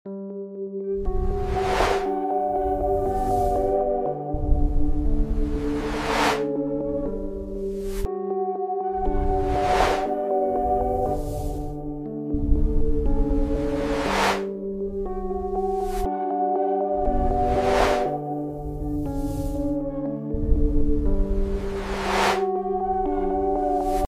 🎧 AI-powered visual & audio ASMR loops to relax your mind and body. From satisfying jelly cuts to nature ripples and cozy cat moments — all crafted by artificial intelligence.